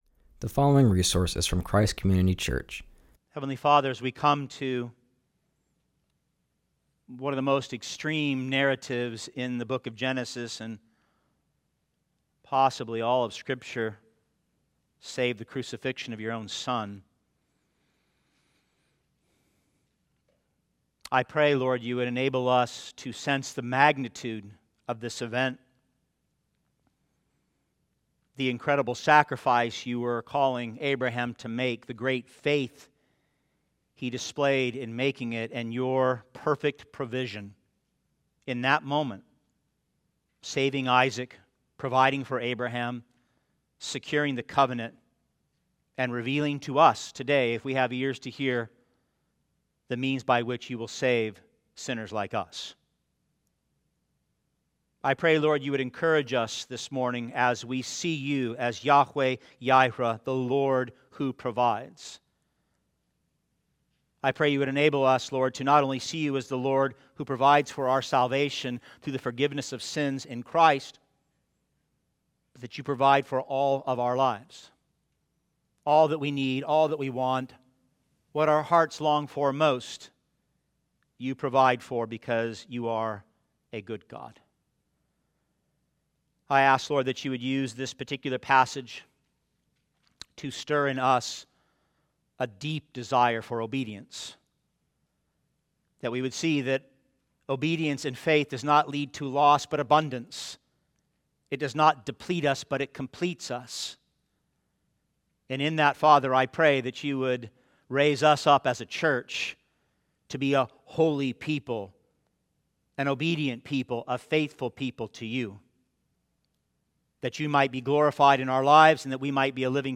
continues our series and preaches from Genesis 22.